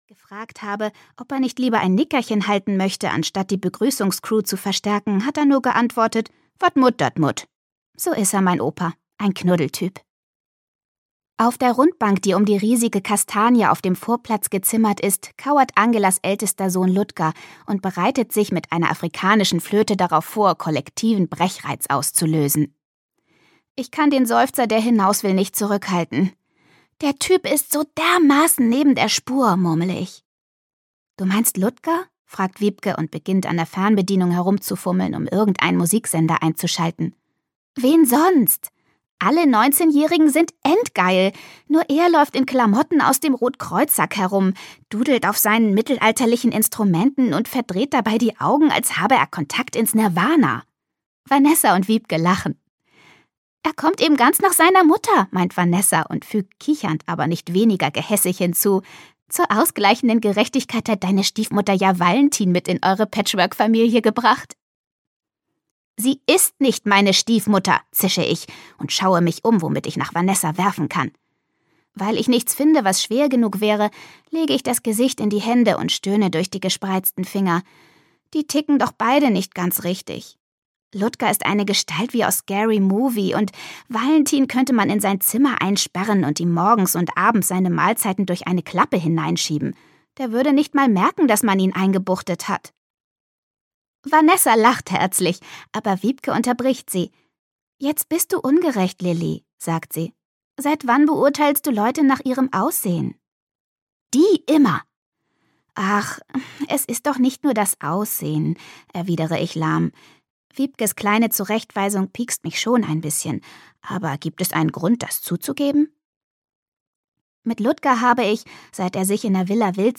Freche Mädchen: Franzosen küssen besser - Martina Sahler - Hörbuch